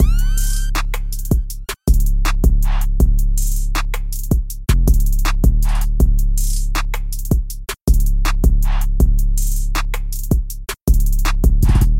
南边的鼓环
描述：嗯 循环与 808
Tag: 160 bpm Hip Hop Loops Drum Loops 2.02 MB wav Key : Unknown FL Studio